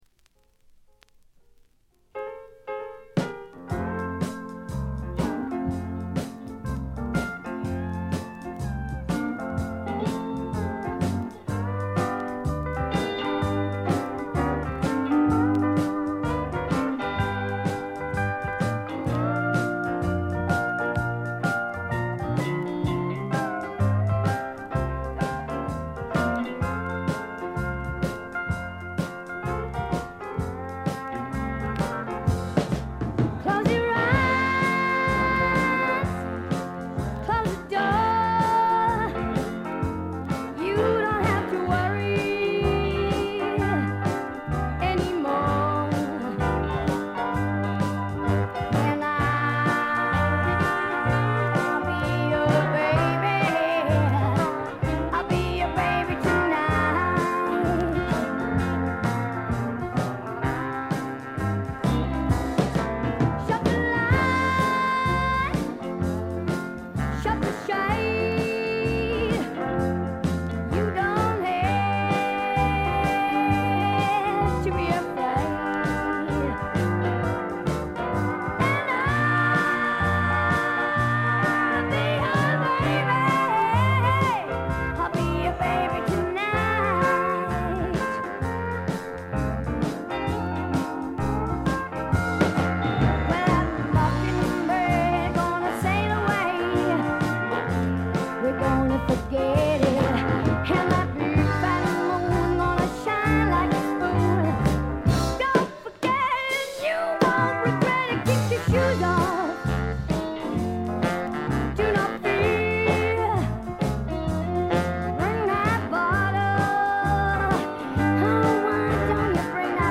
部分試聴ですが、軽微なチリプチ少々。
試聴曲は現品からの取り込み音源です。